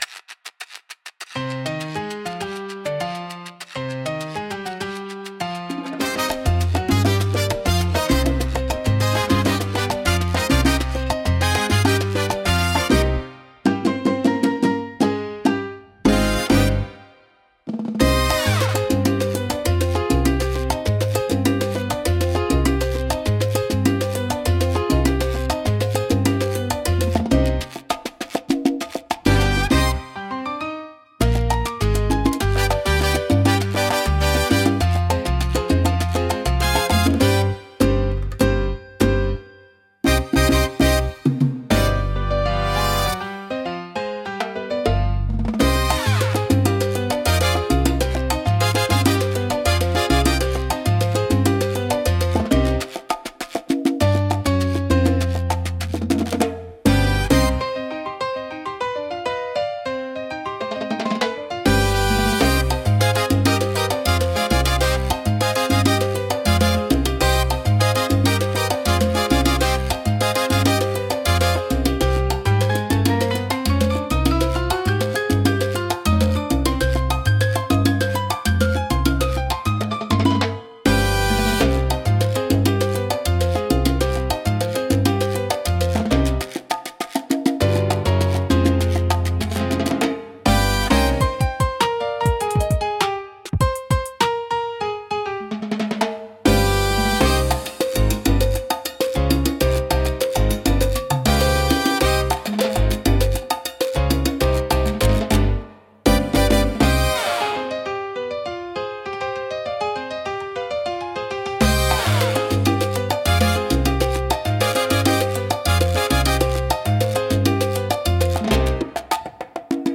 聴く人に活力と情熱を与え、明るく熱気に満ちた空間を作り出します。